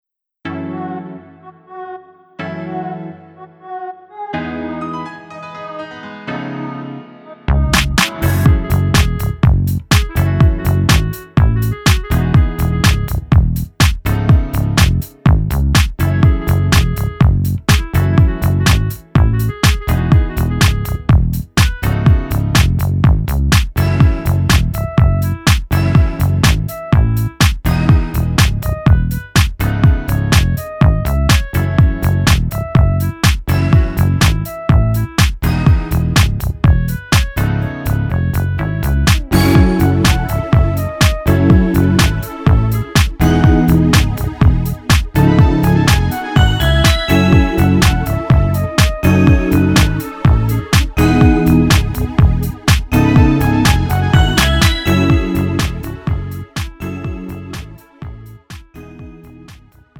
음정 남자키
장르 pop 구분 Pro MR